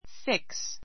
fix A2 fíks ふィ クス 動詞 ❶ 固定する, 取り付ける; （目・心などを） じっと注ぐ fix a mirror to the wall fix a mirror to the wall 壁 かべ に鏡を取り付ける She fixed her eyes on the screen.